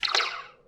Space Shoot Sounds
Some space shoot sounds that I made from the zap instrument in the public domain instrument sample library VSCO 2 CE. I tweaked them in Audacity to sound like an alien spaceship shooting.
alienshoot3.ogg